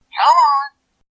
Noiseless version, also cropped out silence.
Fox's sound clip when selected with a Wii Remote.
Fox_Wiimote_Sound.ogg.mp3